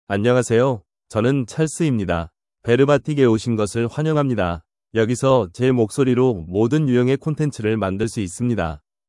Charles — Male Korean (Korea) AI Voice | TTS, Voice Cloning & Video | Verbatik AI
MaleKorean (Korea)
Charles is a male AI voice for Korean (Korea).
Voice sample
Listen to Charles's male Korean voice.
Male